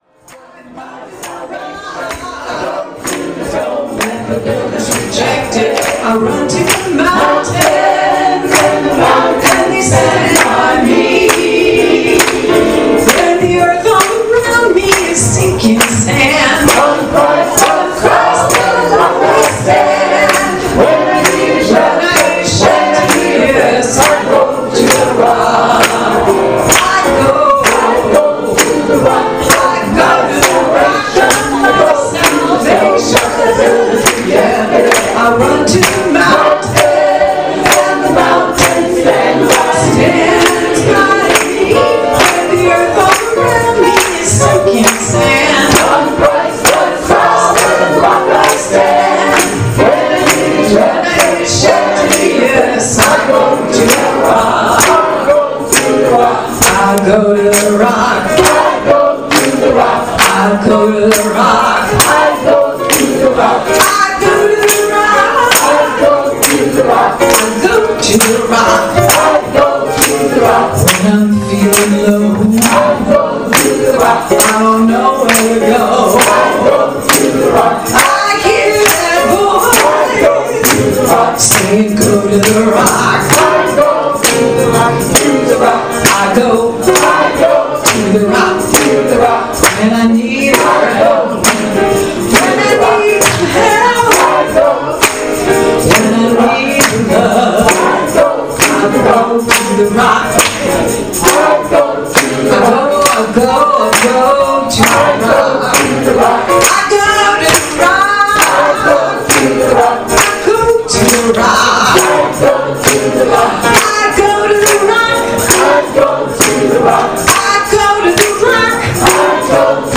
Lighthouse Singers Chorus Out the Spirit – The Lighthouse Singers Gospel Choir (LHS) performed at our Annual Gala as they have in nearly all of the previous live and in-person events in the past.  Please click on the Play arrow (below left) to listen to the audio clip of LHS singing I Go To The Rock, and I Need You To Survive” at our 10th Annual Celebration.
alto and tenor
Thank you LHS for the spirited music you have provided to Haiti On The Rise through the years.